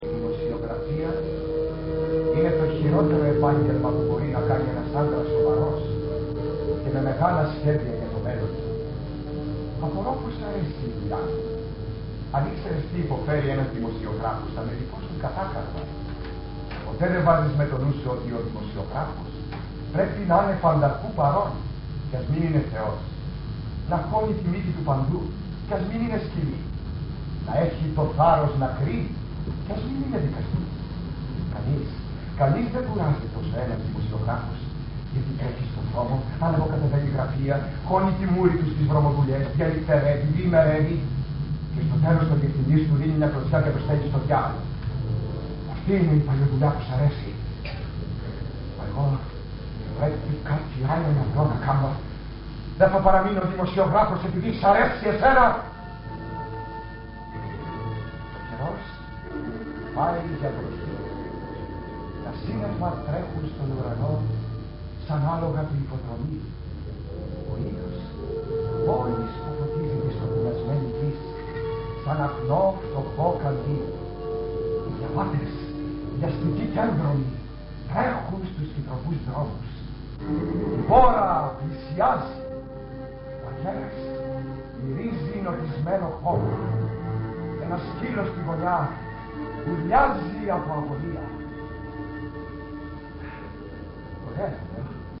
Ηχογράφηση Παράστασης
Αποσπάσματα από την παράσταση
sound δείγμα, διάρκεια 00:01:35, Πράξη 2η, Λέανδρος-Λέανδρος 2